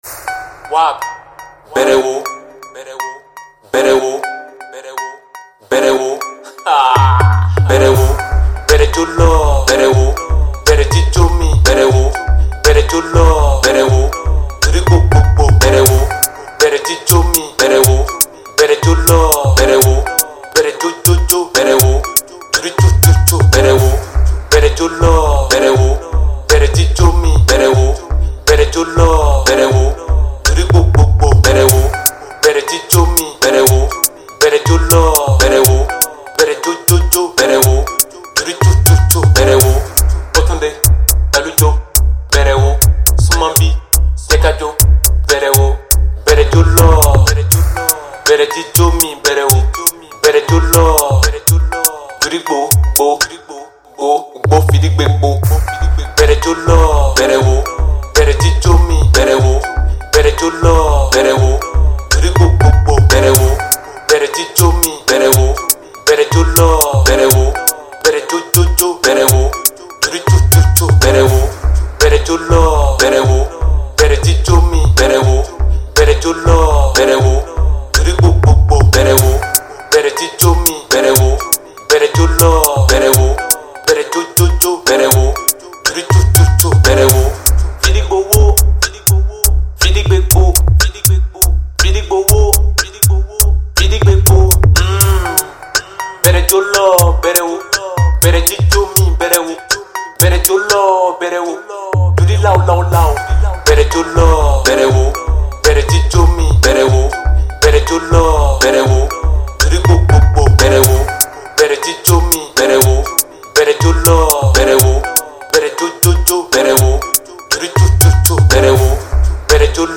Urban Mp3